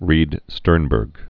(rēdstûrnbûrg)